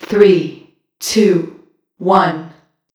bl_voice_countdown_3.ogg